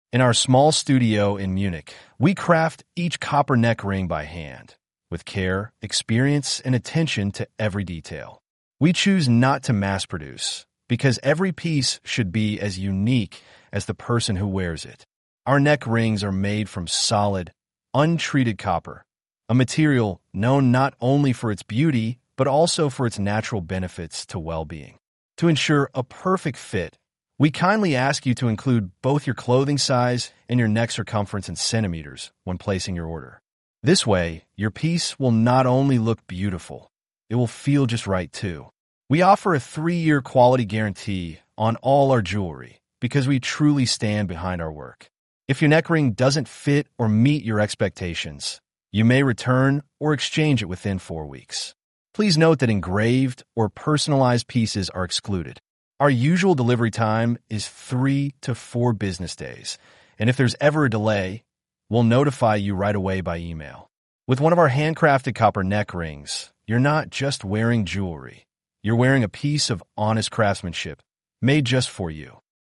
Halsreif-engl-ttsreader.mp3